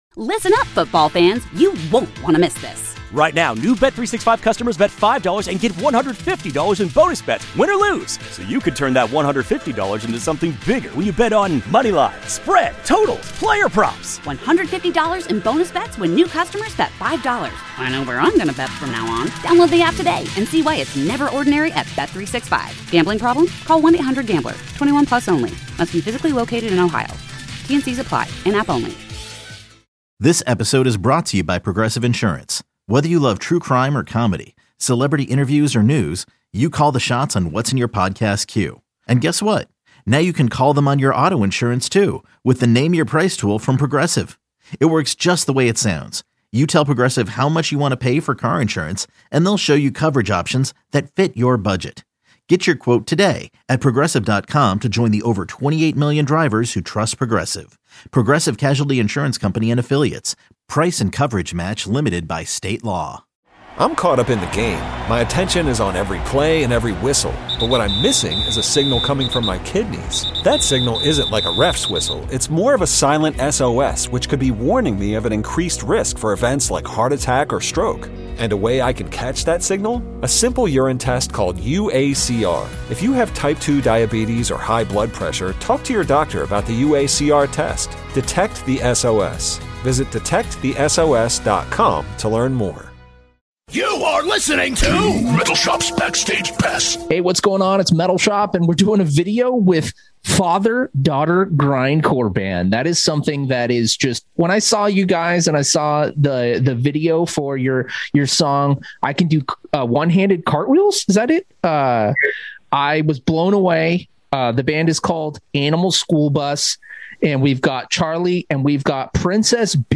I sat down and zoom'd with the band and chatted all about Kids Bop, Mother's Day, jiu jitsu and more.